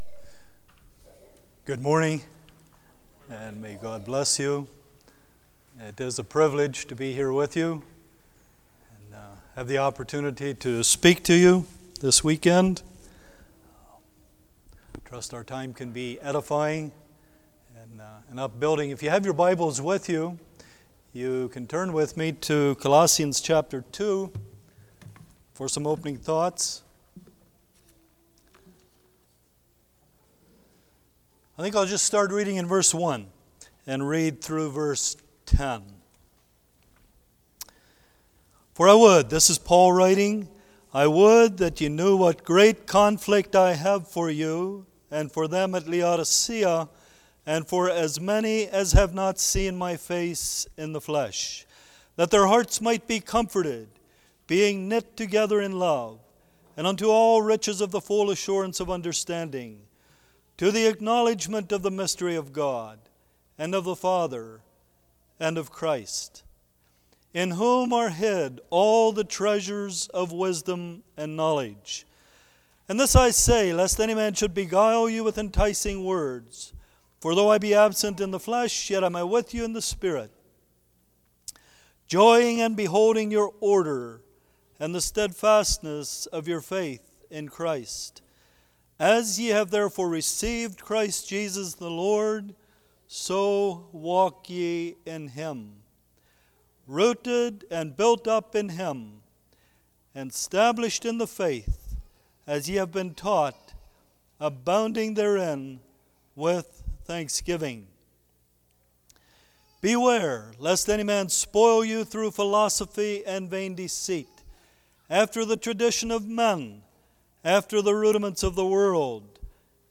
Service Type: Saturday Morning